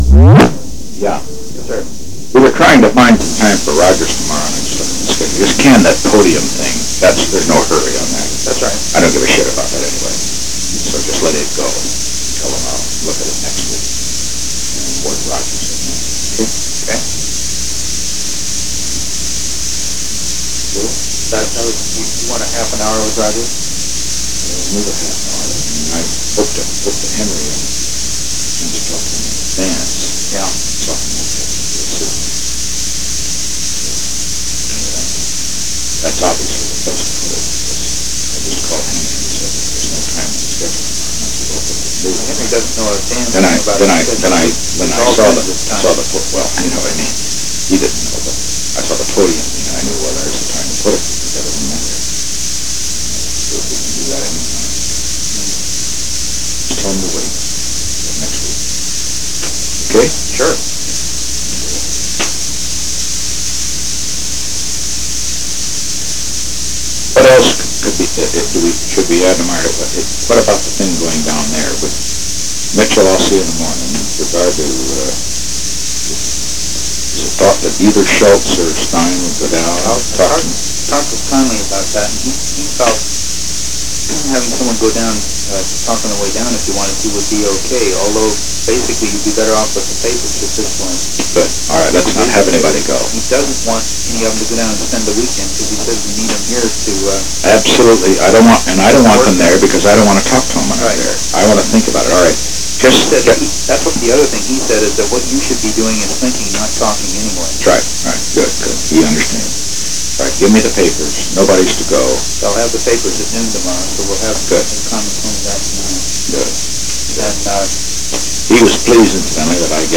Secret White House Tapes
Location: White House Telephone
The President talked with H. R. (“Bob”) Haldeman.